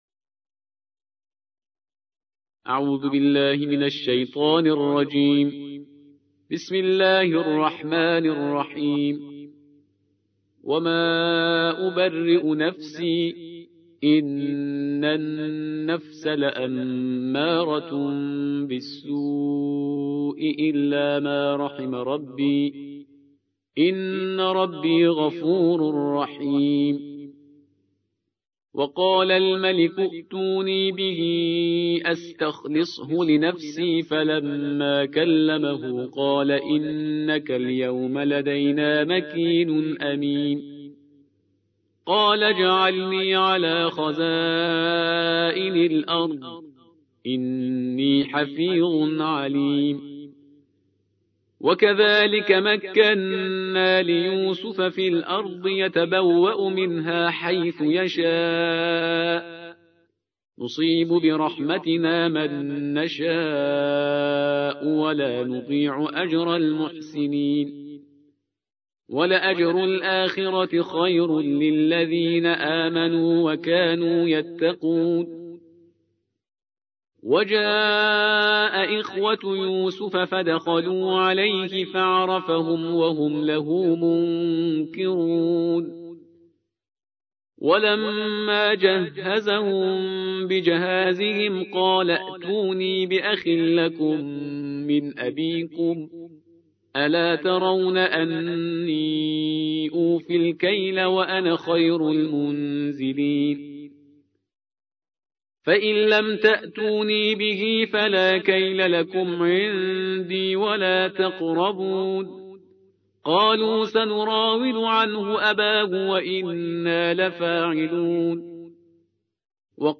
الجزء الثالث عشر / القارئ